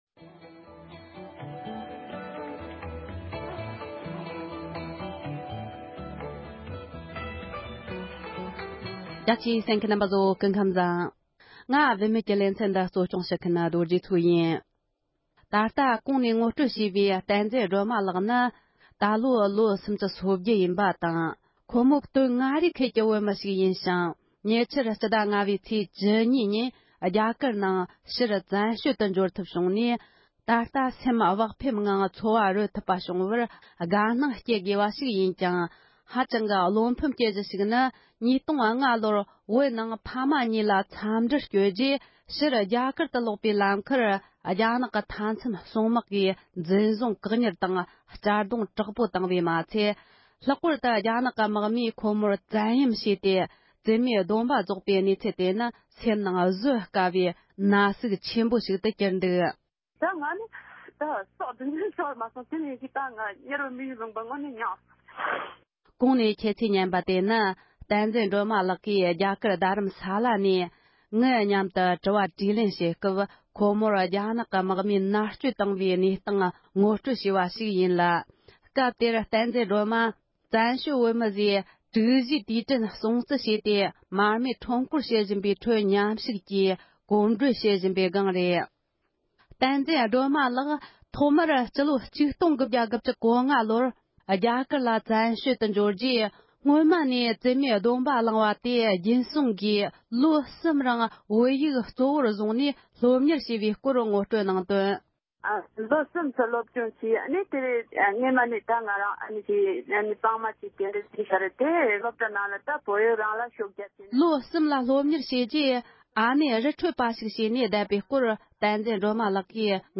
བཀའ་འདྲི་ཞུས་པ་ཞིག་ལ་གསན་རོགས་ཞུ།